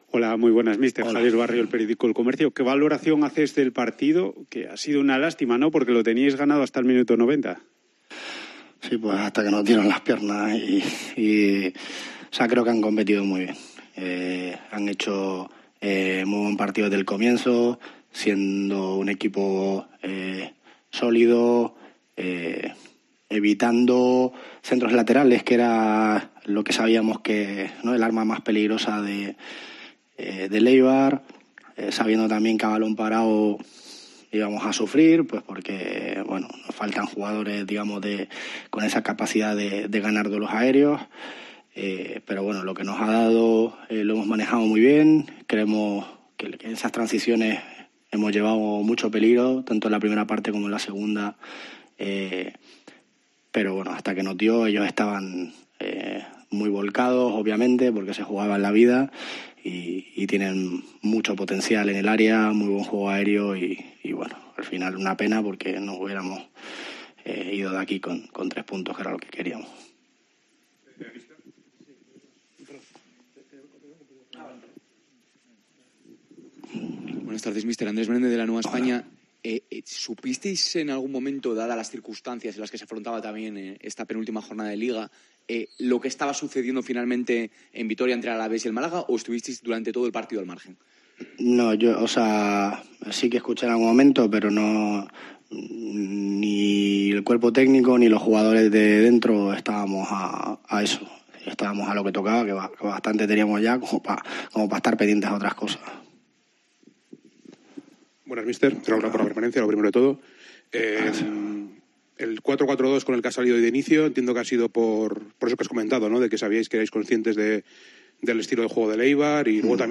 Miguel Ángel Ramírez compareció en sala de prensa después del empate (2-2) frente a la SD Eibar, tras conseguir la permanencia en Segunda División.